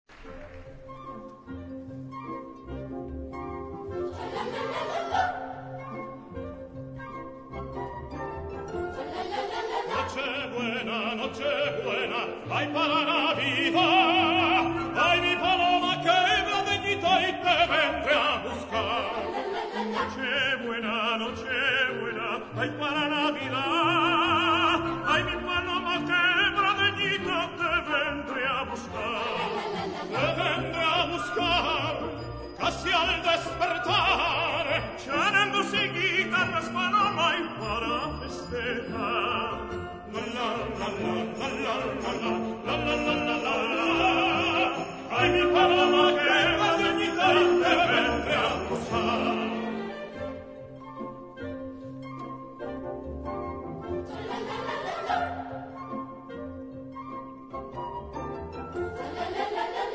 key: E-major